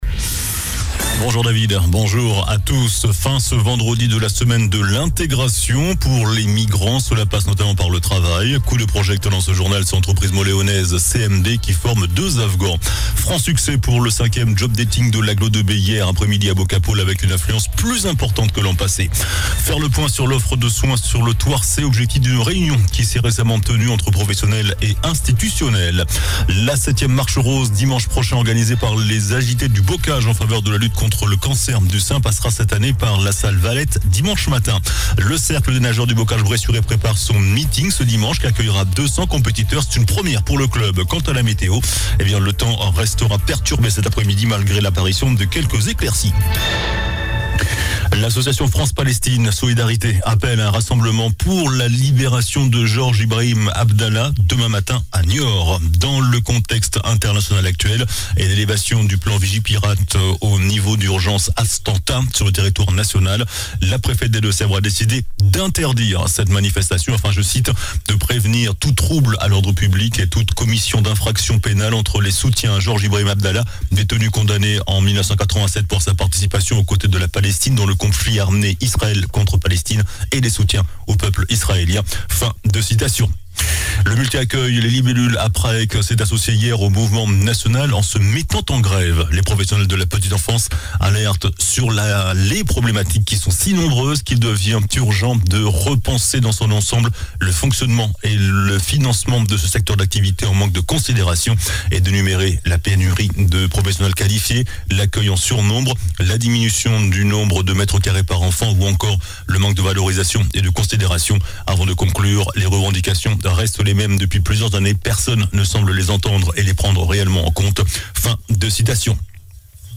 JOURNAL DU VENDREDI 20 OCTOBRE ( MIDI )